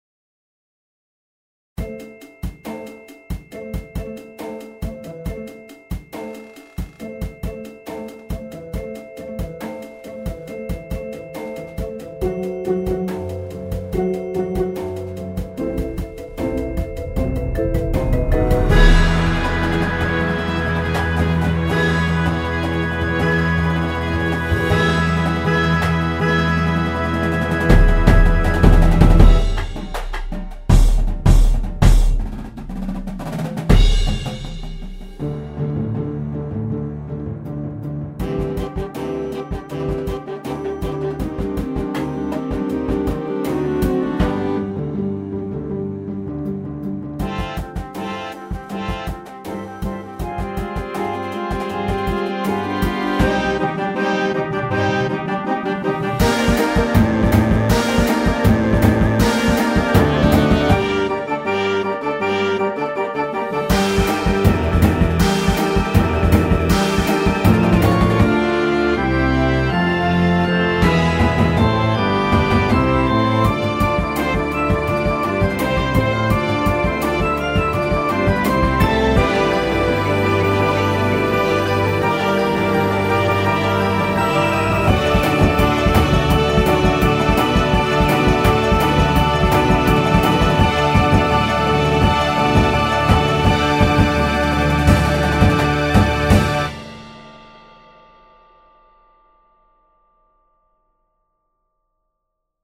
(Hip-Hop)
Piccolo
Clarinet 1, 2, 3
Trumpet 1, 2, 3
Snare Line